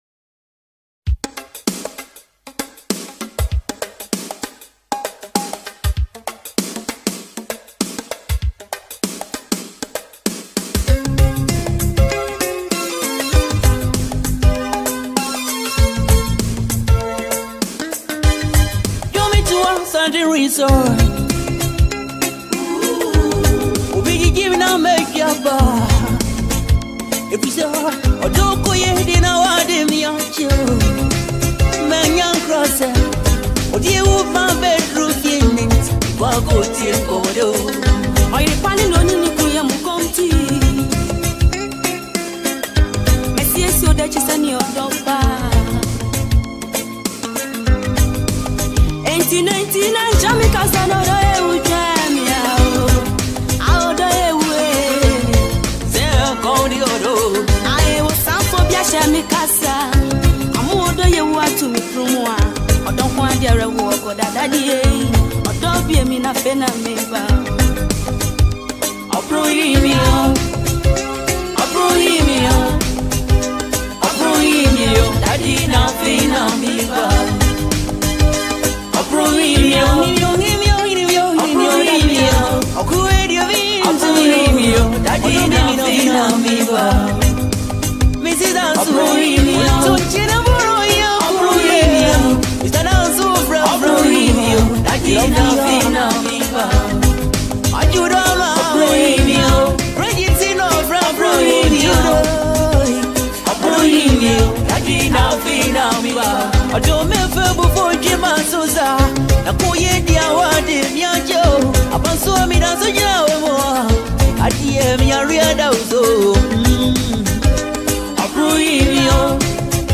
Experience the magic of pure Ghanaian highlife music.